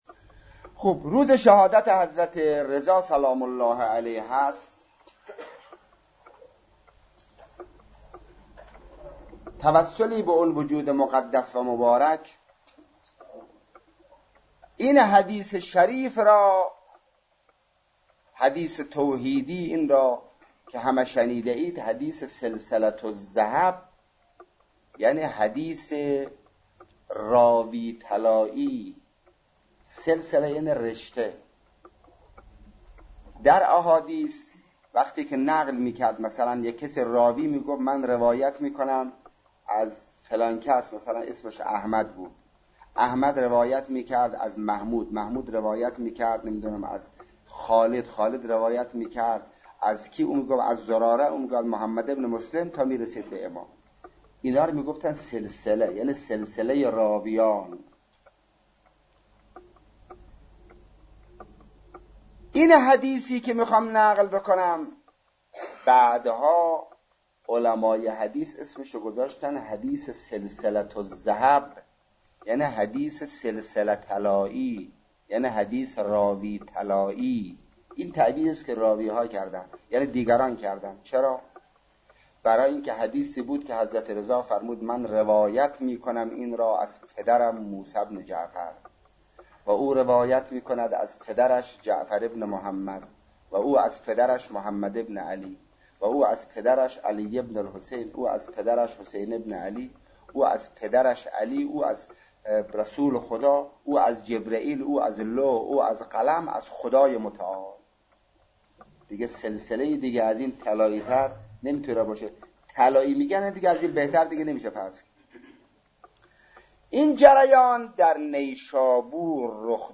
به مناسبت فرا رسیدن سالروز شهادت حضرت امام رضا(ع)، صوت سخنرانی شهید مطهری درباره حدیث سلسلةالذهب را می‌شنویم.